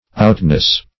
outness - definition of outness - synonyms, pronunciation, spelling from Free Dictionary
Outness \Out"ness\, n.